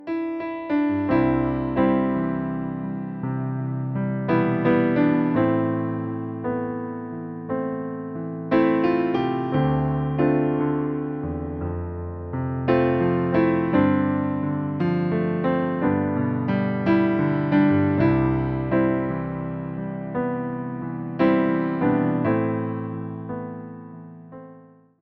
Podkład fortepianowy
Wersja demonstracyjna:
57 BPM
C – dur